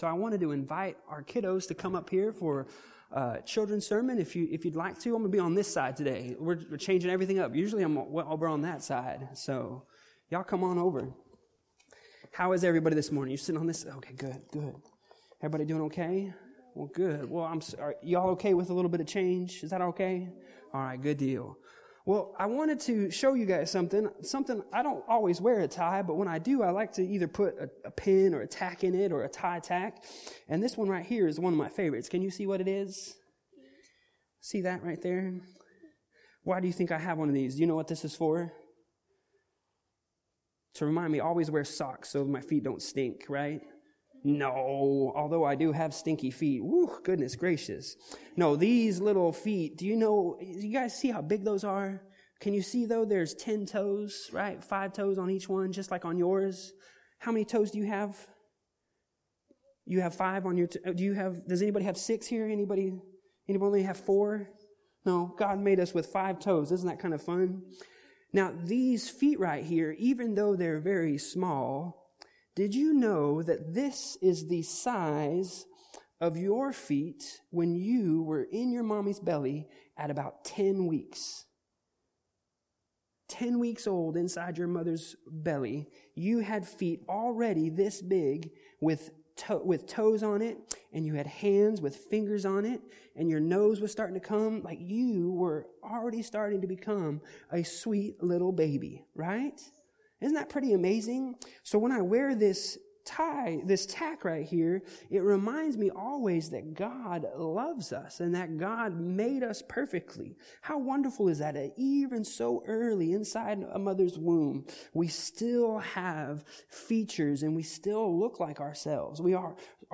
Passage: Matthew 18:10-14 Service Type: Sunday Morning